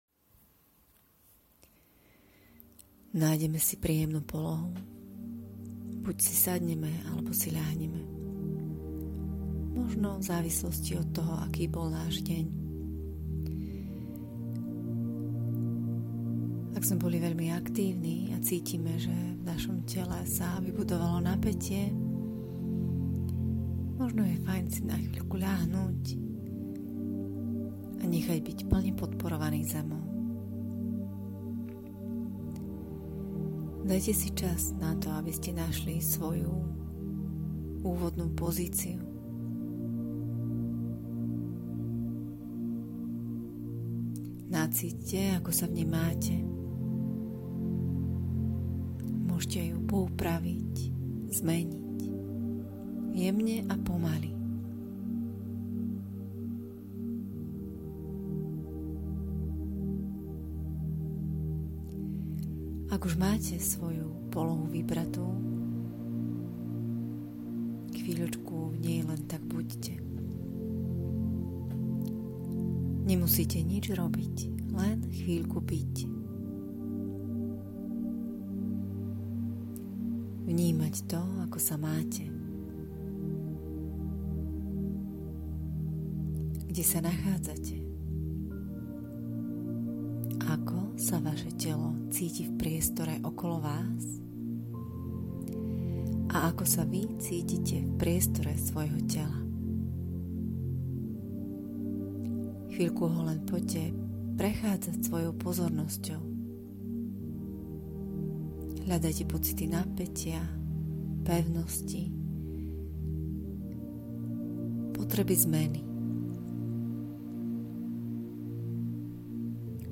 Relaxácia pred cvičením
Relaxácia pred cvičením.mp3